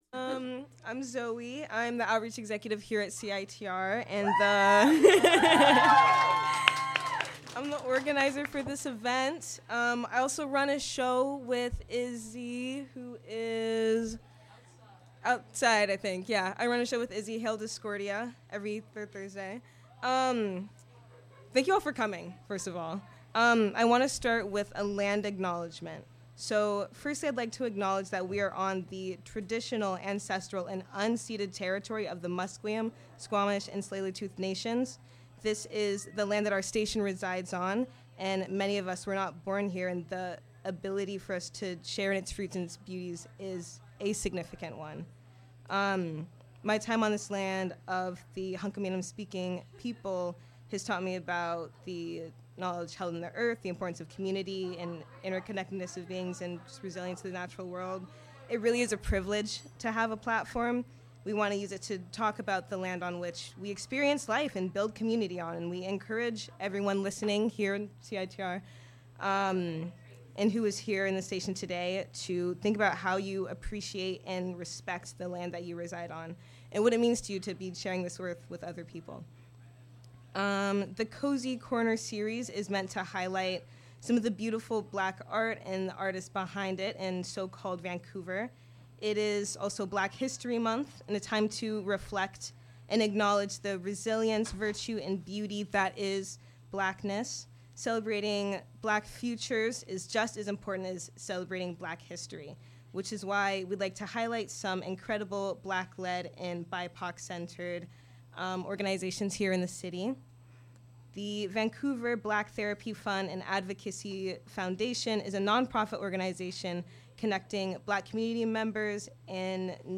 Recorded live, February 16, 2024.
Live Set